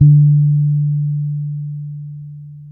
-MM DUB  D 4.wav